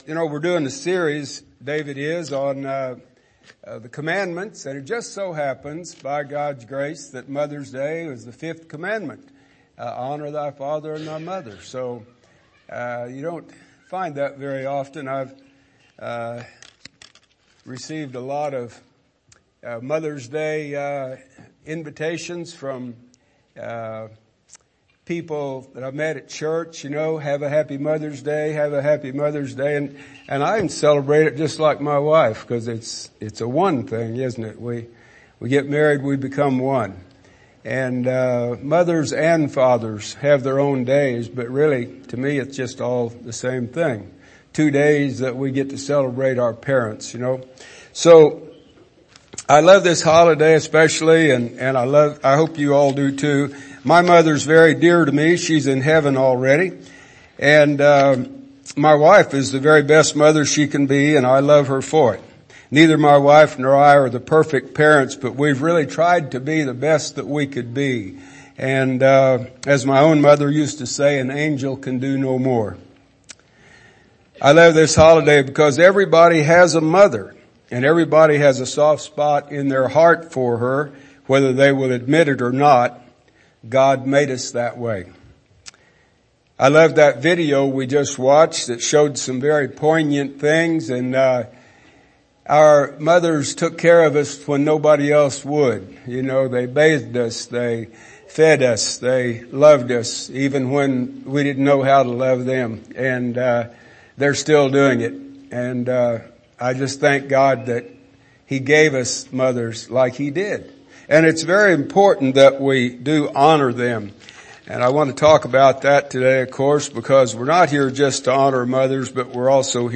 God's Top Ten Service Type: Morning Service « Acts